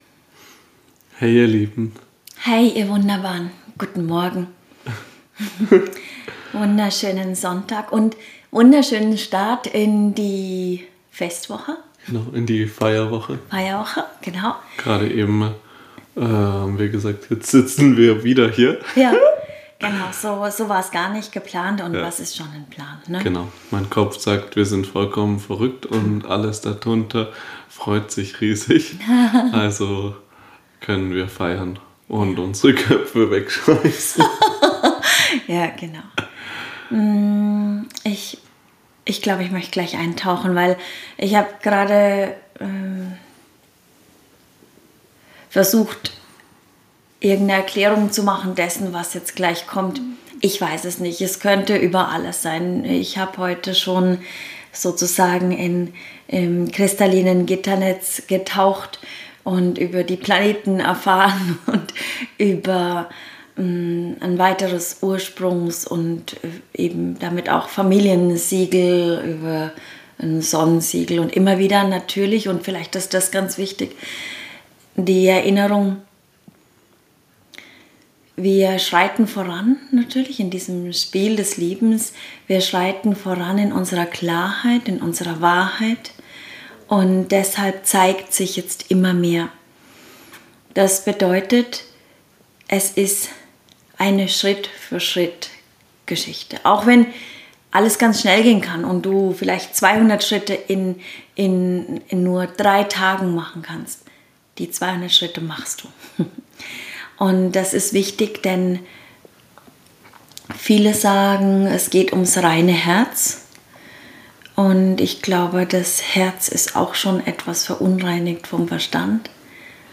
Channeling | Siegel des Ursprungs - das FamilienNetz ~ MenschSein - musst du leben.